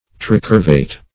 Search Result for " tricurvate" : The Collaborative International Dictionary of English v.0.48: Tricurvate \Tri*cur"vate\ (tr[-i]*k[^u]r"v[asl]t), a. [Pref. tri- + curvate.]
tricurvate.mp3